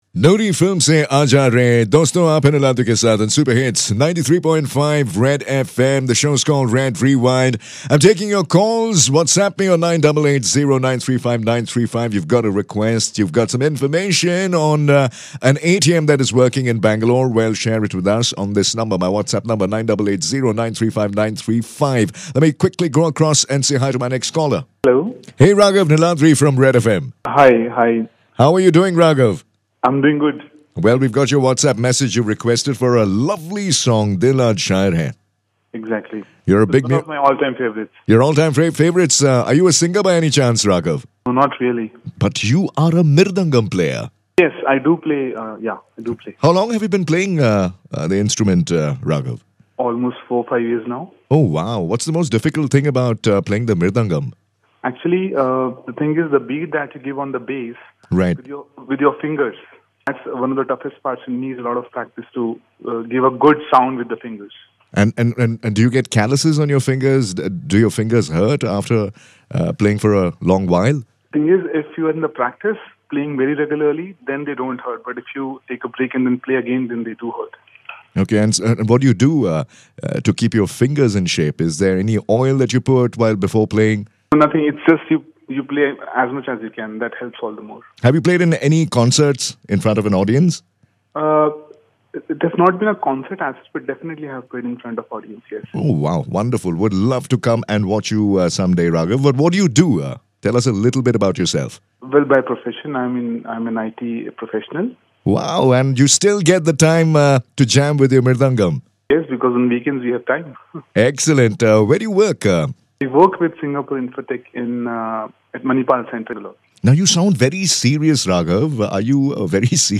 When a Mridangam player takes to singing... Its not all that bad...